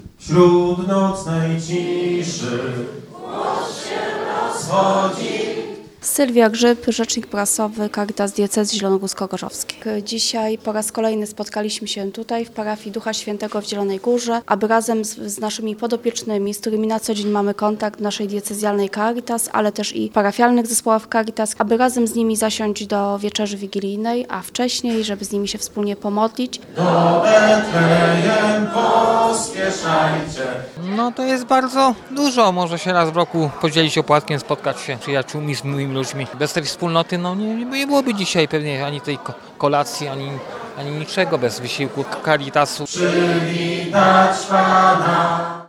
Wczoraj odbyła się wigilia Caritas dla osób bezdomnych i potrzebujących .
Biskup Tadeusz Lityński rozpoczął uroczystość od błogosławieństwa, następnie łamano się opłatkiem i śpiewano kolędy:
Światełko Betlejemskie oraz oprawę muzyczną zapewnili zielonogórscy harcerze.